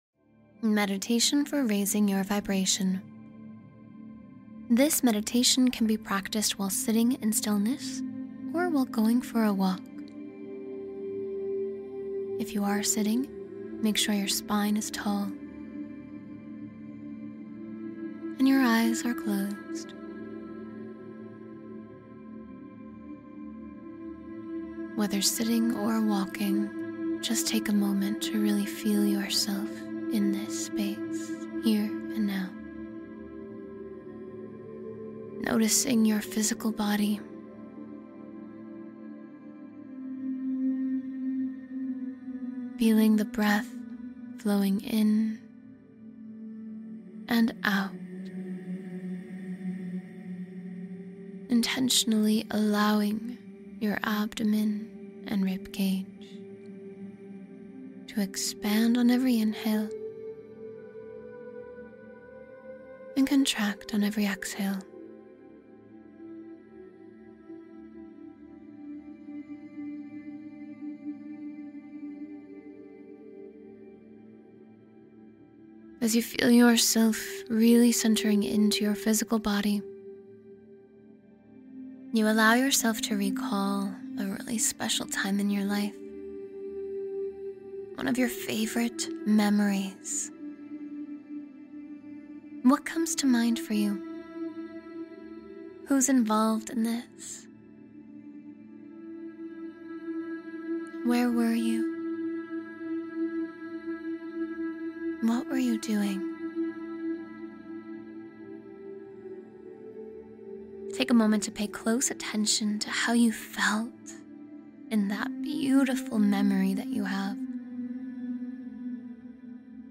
Guided mindfulness exercises